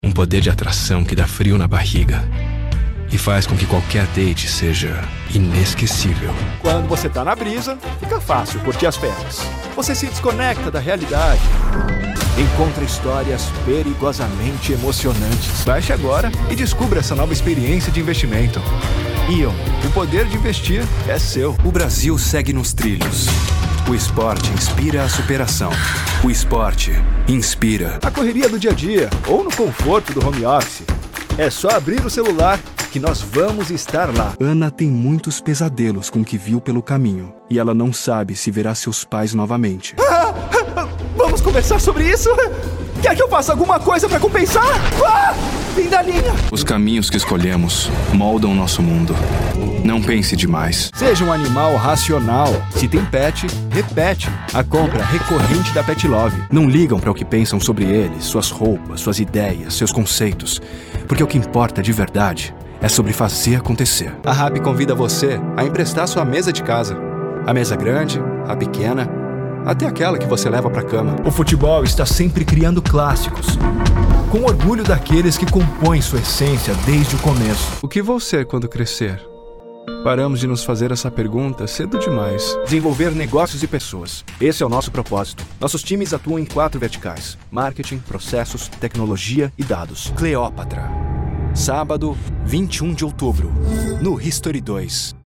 Locutor | Narrador | Ator de voz original